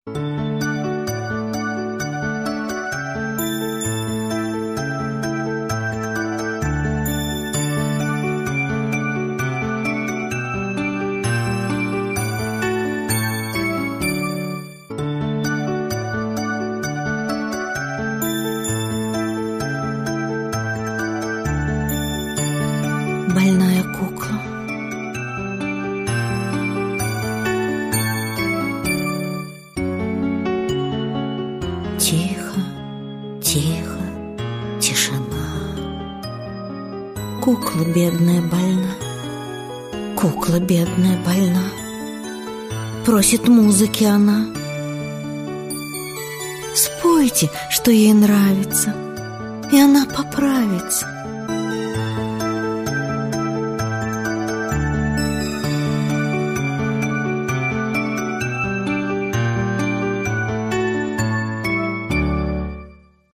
На данной странице вы можете слушать онлайн бесплатно и скачать аудиокнигу "Больная кукла" писателя Валентин Берестов. Включайте аудиосказку и прослушивайте её на сайте в хорошем качестве.